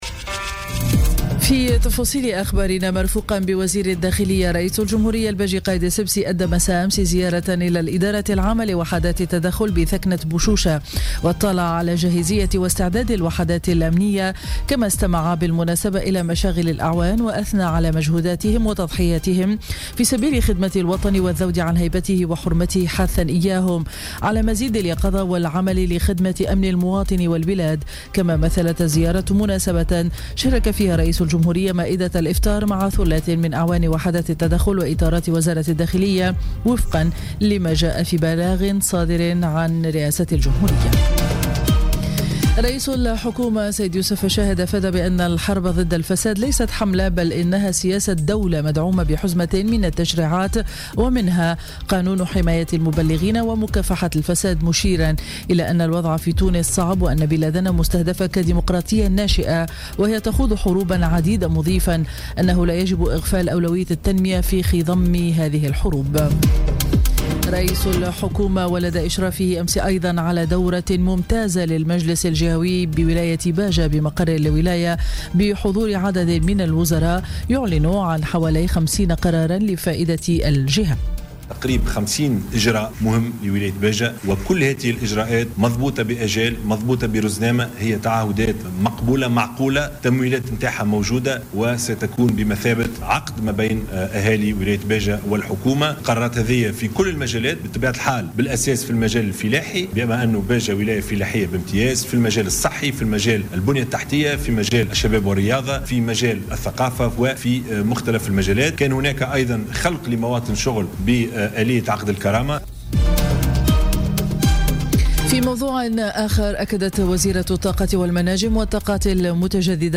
نشرة أخبار السابعة صباحا ليوم الجمعة 9 جوان 2017